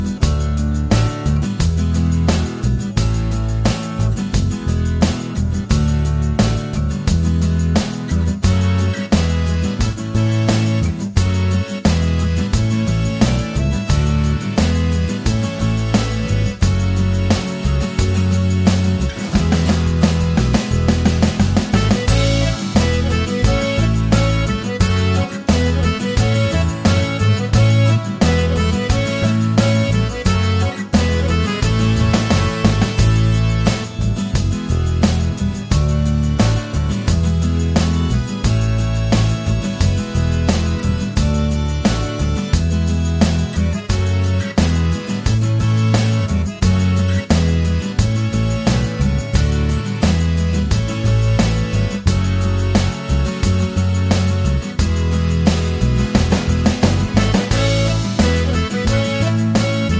Irish